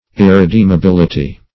Search Result for " irredeemability" : The Collaborative International Dictionary of English v.0.48: Irredeemability \Ir`re*deem`a*bil"i*ty\, n. The state or quality of being irredeemable; irredeemableness.